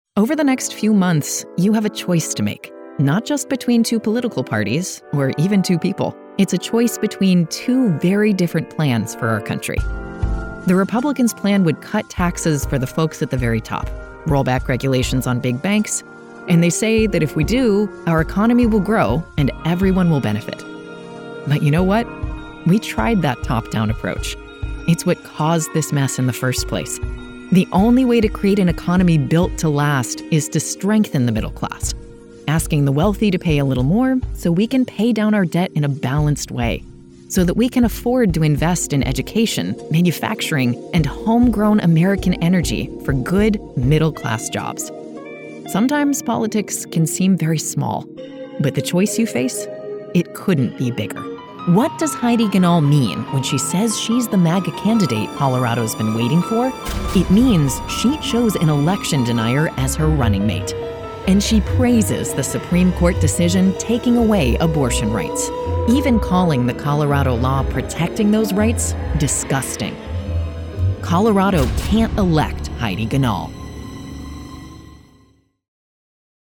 Female Voice Over, Dan Wachs Talent Agency.
Female Democratic Voices
All with excellent home studios and paid Source Connect.